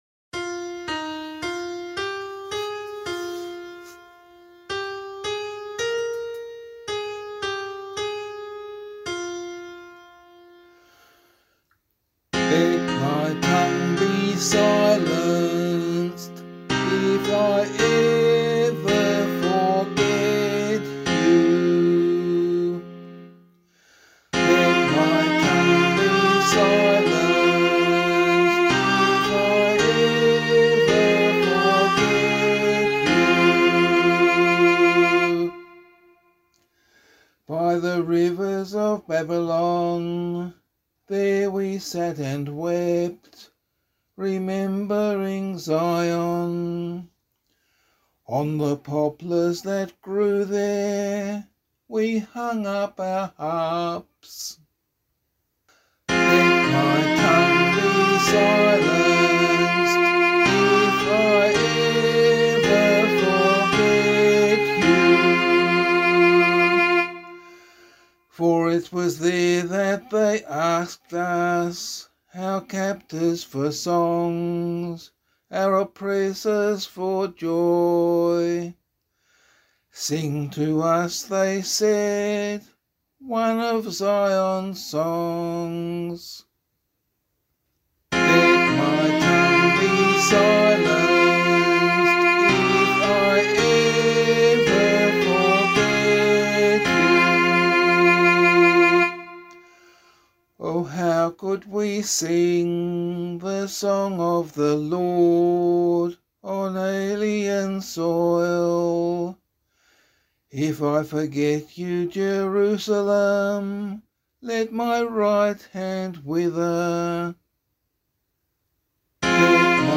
016 Lent 4 Psalm B [LiturgyShare 2 - Oz] - vocal.mp3